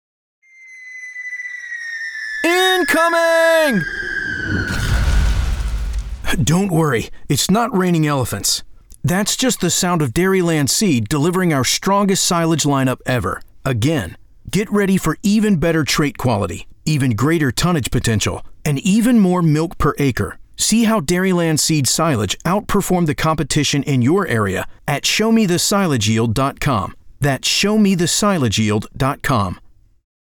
RADIO
In keeping with the concept, we sought to leap out of the sea of sameness with disruptive sound effects and unapologetic confidence in our seed performance.